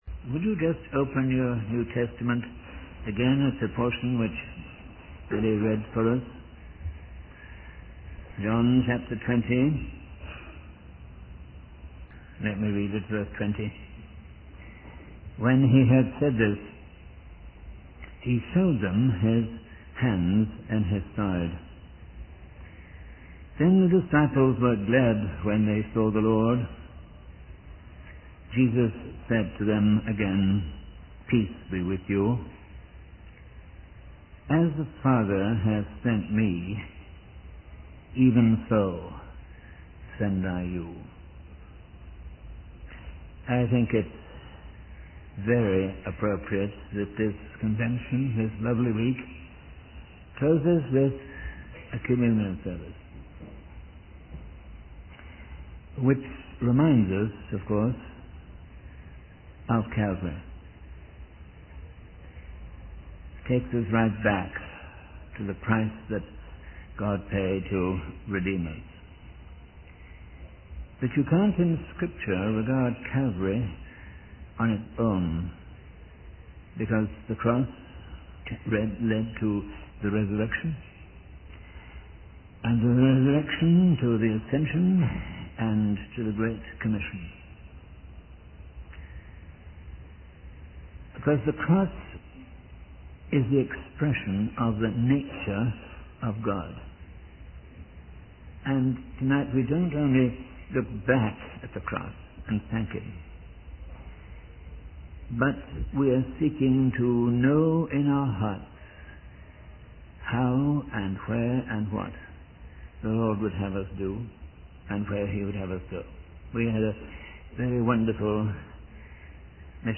43_John_20_Communion_Service.mp3